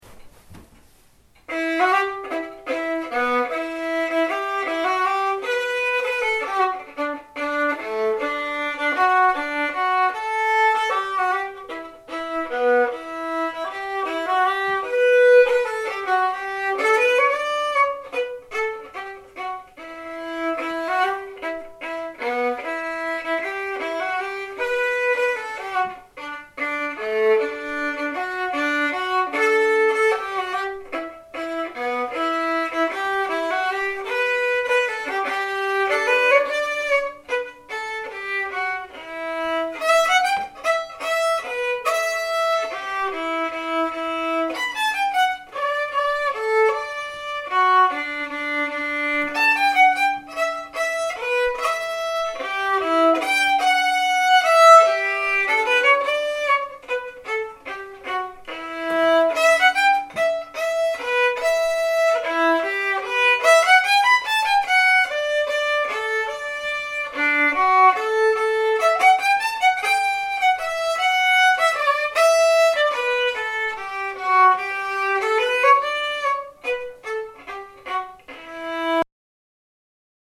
Waltz - E Minor